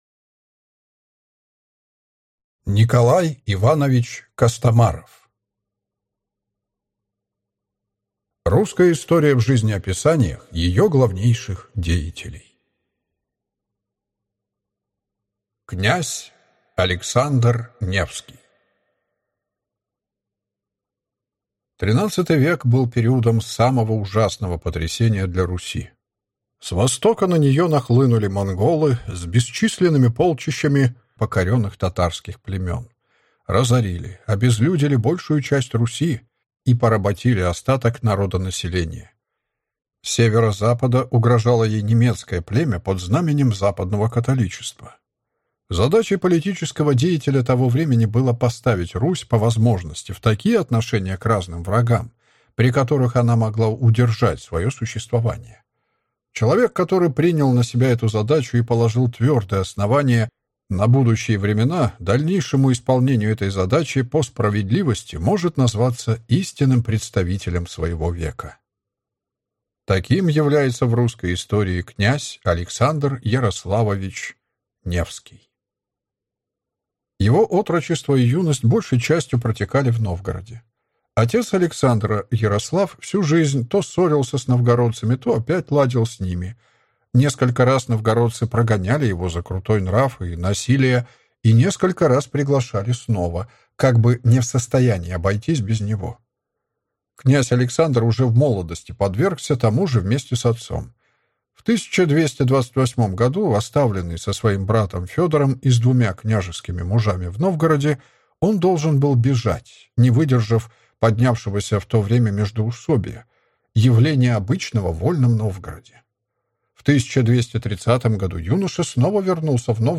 Аудиокнига Русская история в жизнеописаниях ее главнейших деятелей. Князь Александр Невский | Библиотека аудиокниг